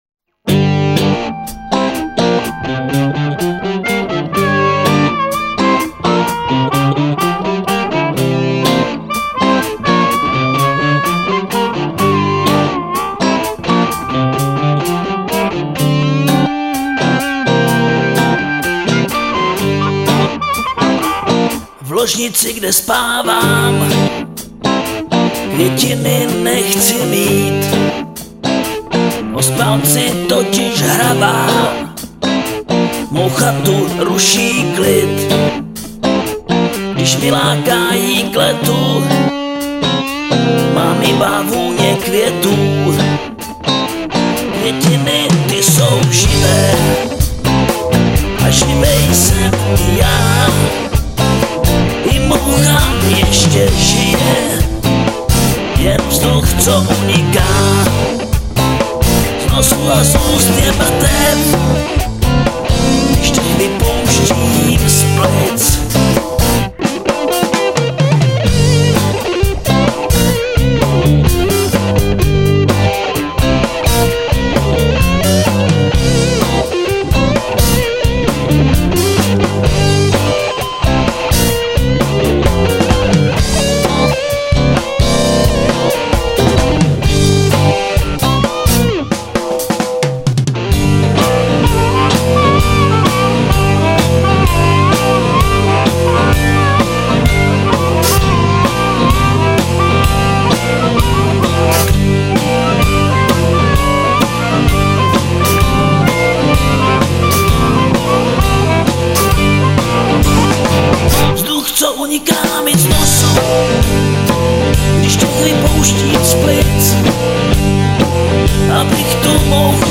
Ve skladbách se mísí jižanský rock s poctivým blues.